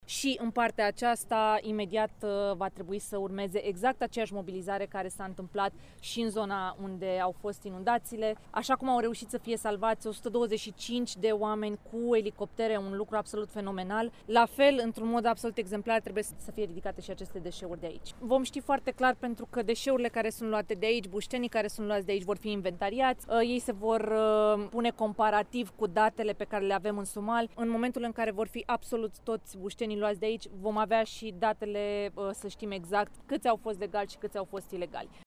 Ministrul Mediului, Diana Buzoianu, a declarat ieri, la viaductul de la Poiana Largului, că trebuie luate de urgenţă măsuri pentru scoaterea din apă a resturilor aduse de viitura produsă în noaptea de duminică spre luni, întrucât există un risc pentru sănătatea oamenilor, având în vedere că sunt şi animale moarte.